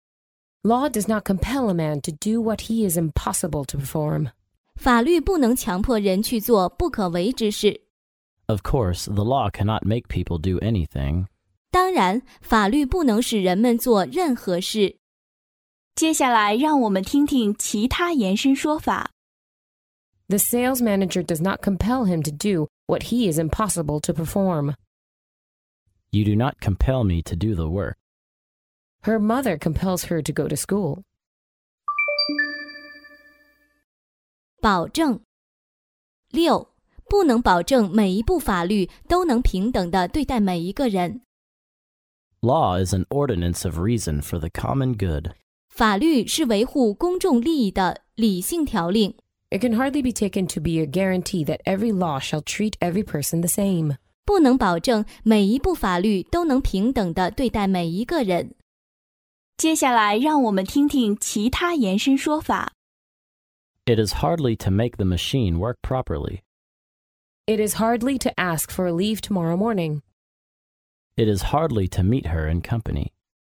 在线英语听力室法律英语就该这么说 第33期:法律不能强迫人去做不可为之事的听力文件下载,《法律英语就该这么说》栏目收录各种特定情境中的常用法律英语。真人发音的朗读版帮助网友熟读熟记，在工作中举一反三，游刃有余。